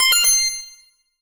Menu_Navigation02_Save.wav